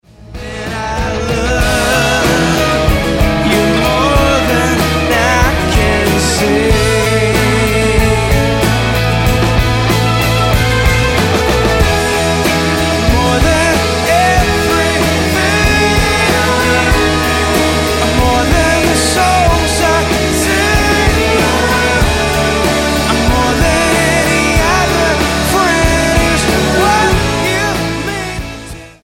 STYLE: Rock
This live recording captures the worship at the event